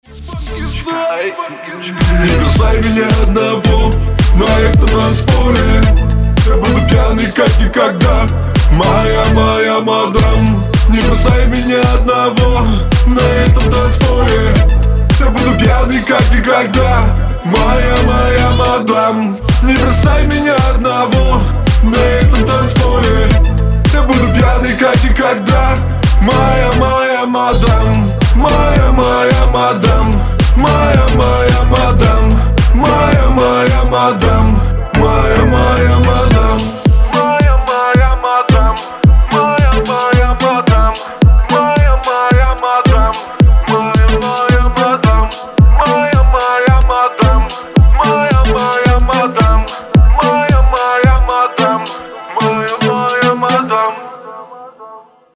• Качество: 320, Stereo
поп
ритмичные
громкие
Хип-хоп
house